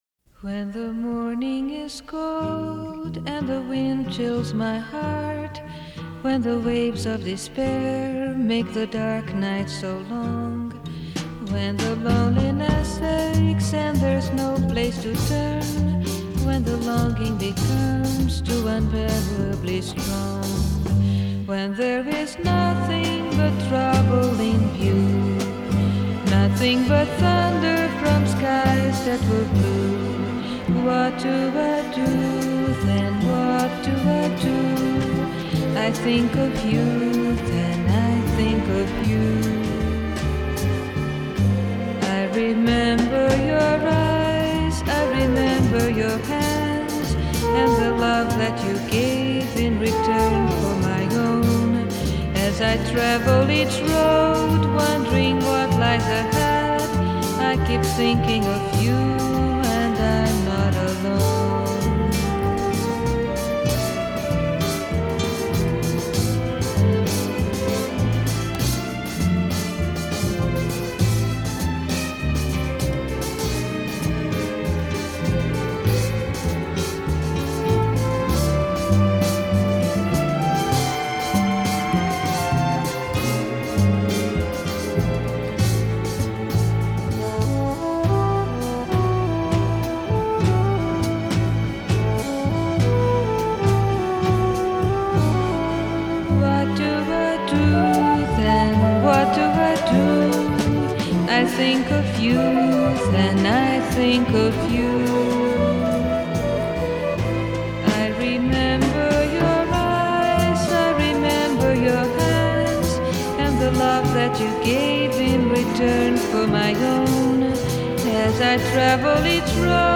Жанр: Vocal, Jazz